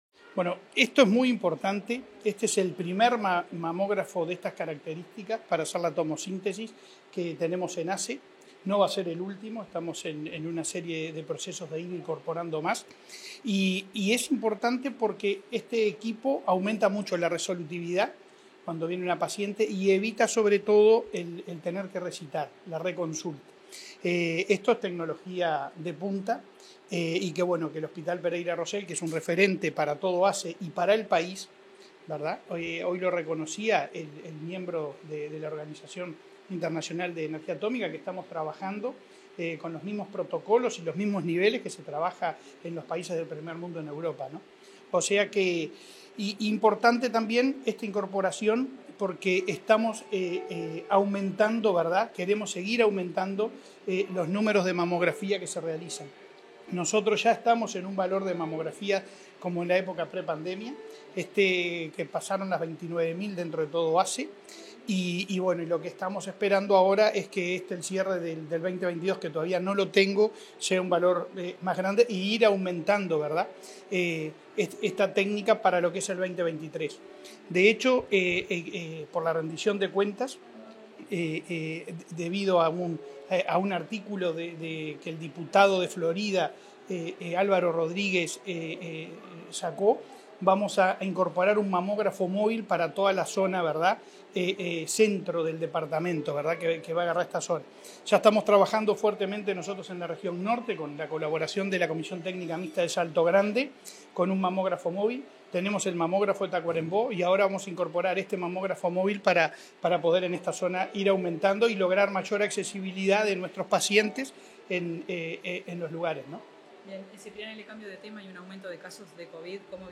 Declaraciones del presidente de ASSE, Leonardo Cipriani, en el Centro Hospitalario Pereira Rossell
Tras el evento, el presidente de la Administración de los Servicios de Salud del Estado (ASSE), Leonardo Cipriani, dialogó con la prensa.